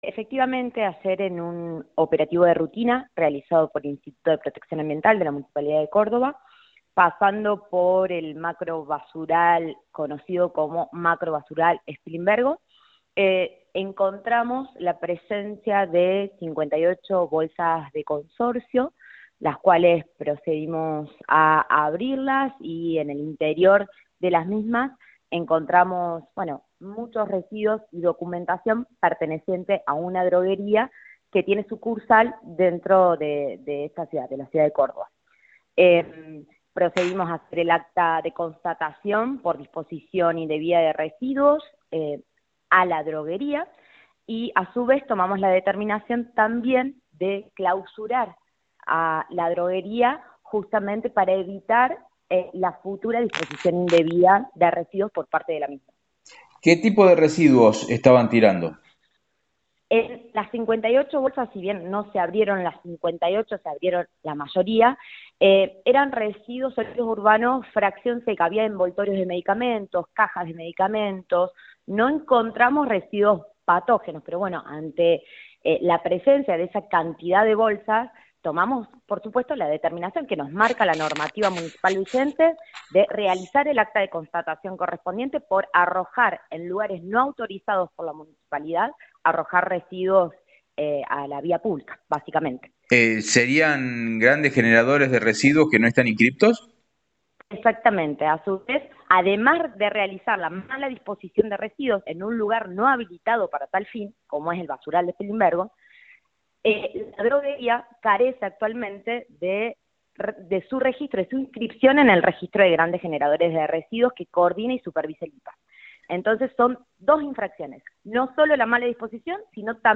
Audio: María Victoria Suárez (Directora Gral. IPAA).